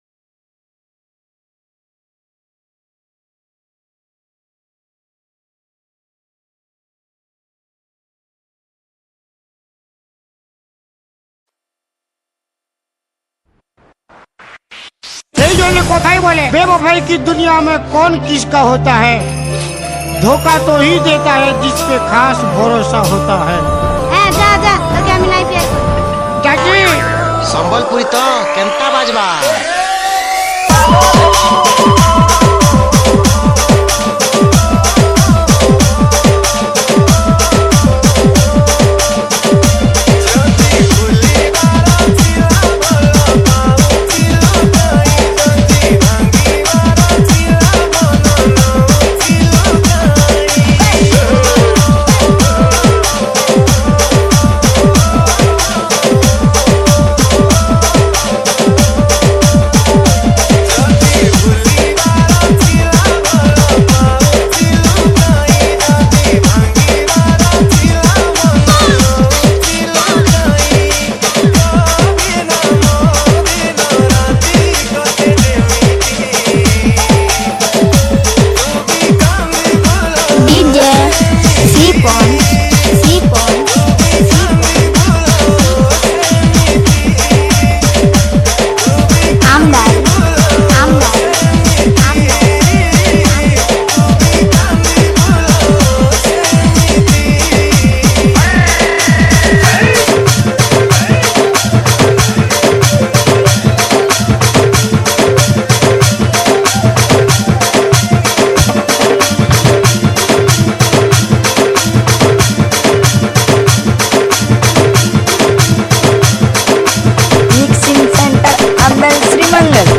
ODIA SAD DJ REMIX